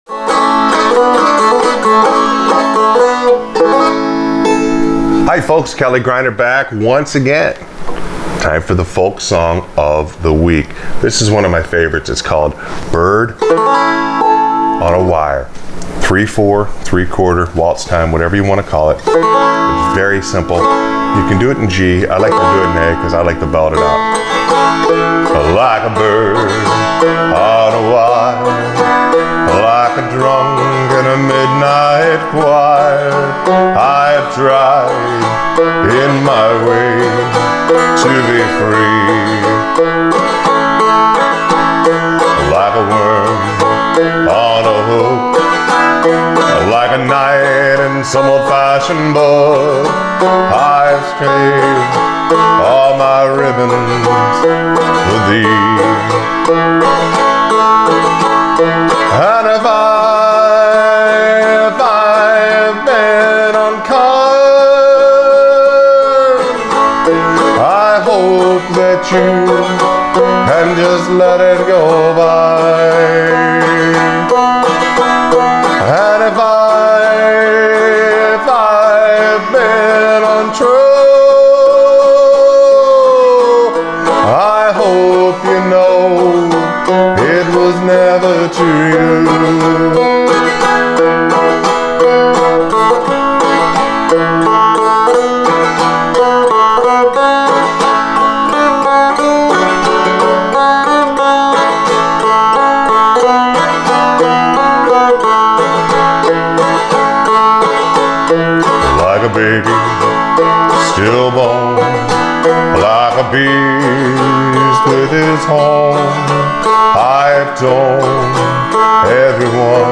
Folk Song Of The Day – Bird On A Wire on Frailing Banjo
Don’t let the 3/4 time throw you.